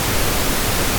noise.mp3